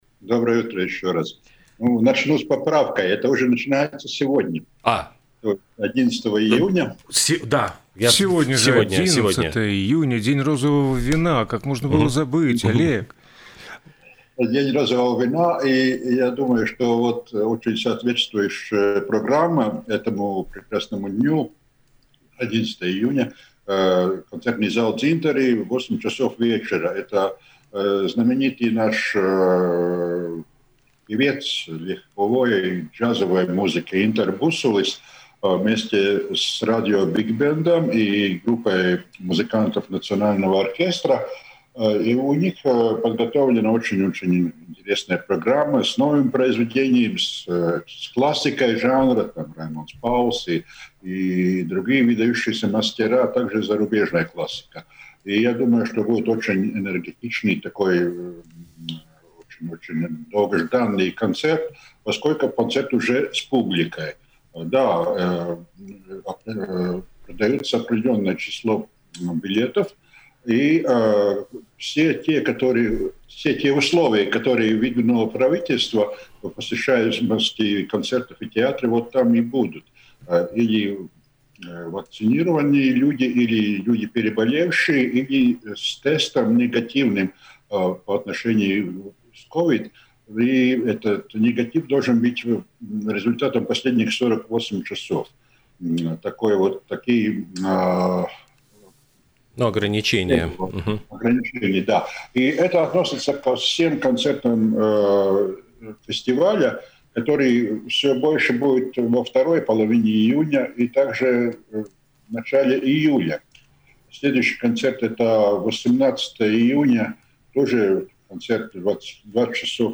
в эфире радио Baltkom